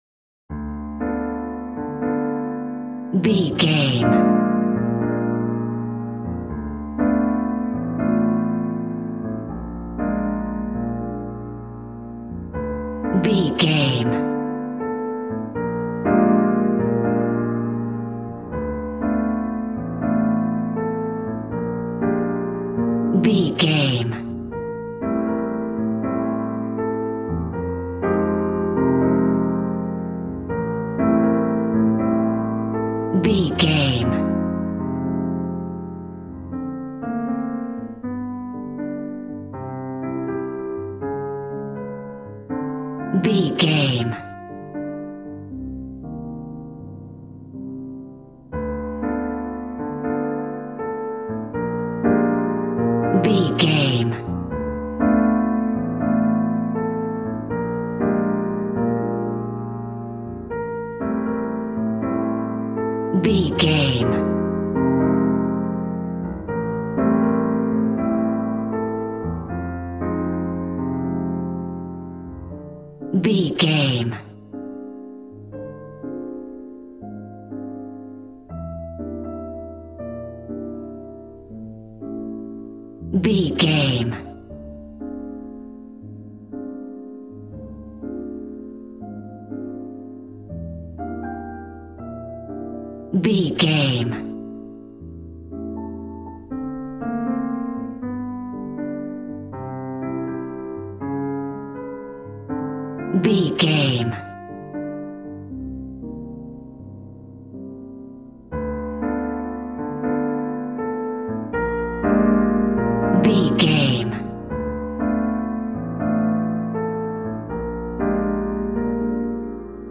Smooth jazz piano mixed with jazz bass and cool jazz drums.,
Ionian/Major
cool